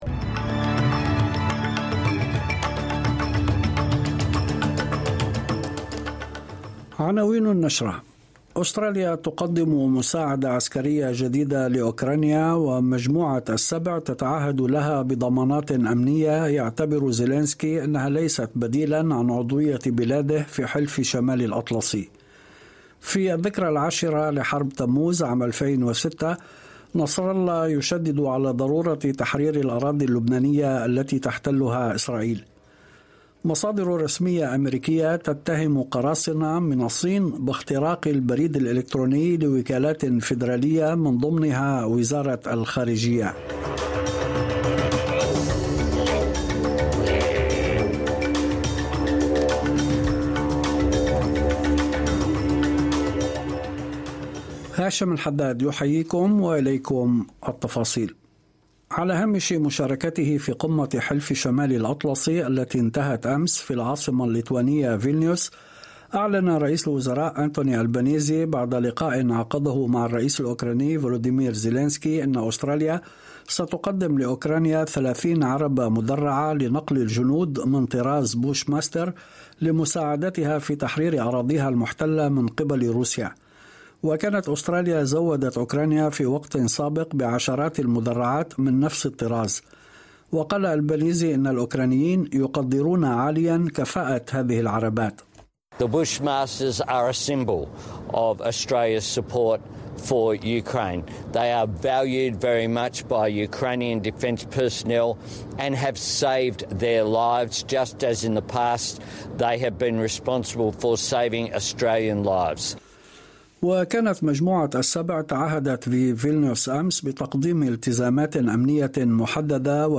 نشرة أخبار المساء 13/07/2023